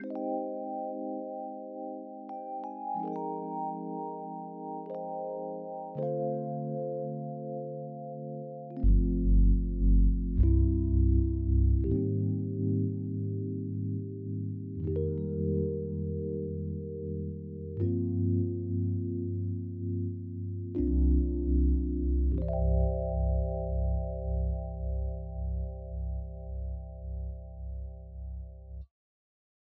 06 rhodes C.wav